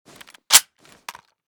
sks_close.ogg.bak